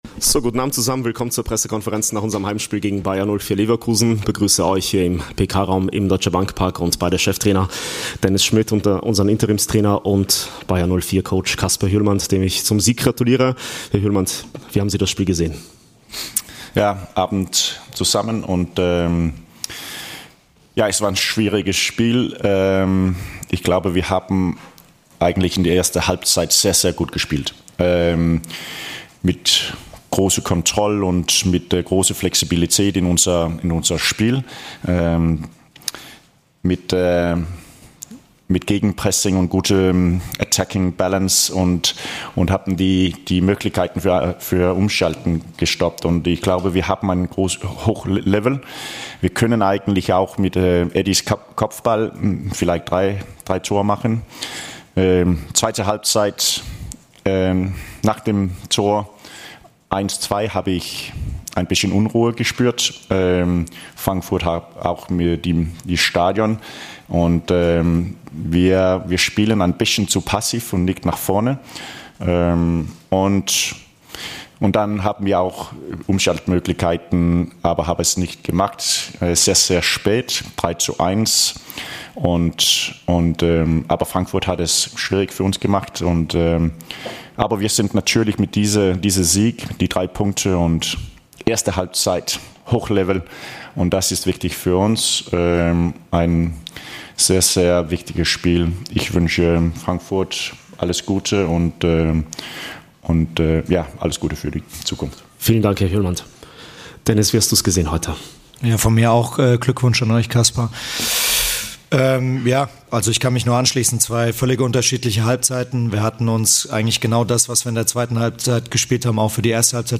"Erst zu passiv, dann starke Halbzeit" I Pressekonferenz nach Eintracht - Bayer Leverkusen